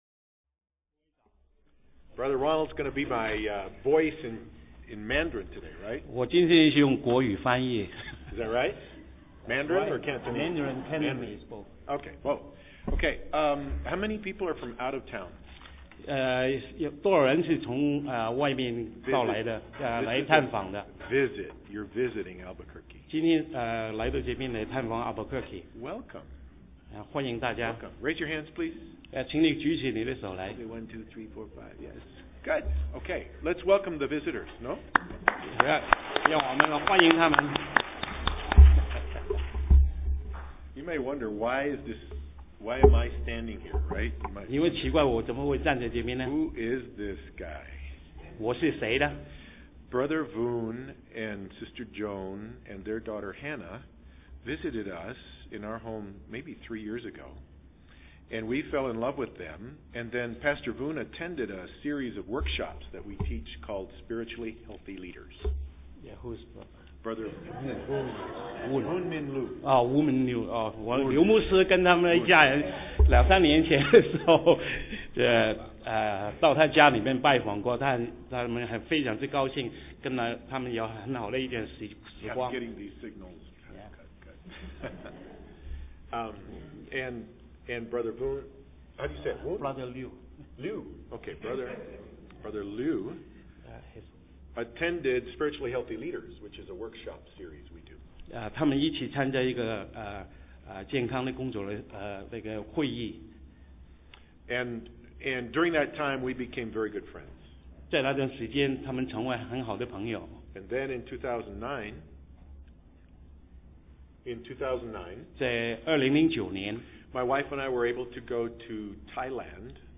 Sermon 2010-12-26 Gifts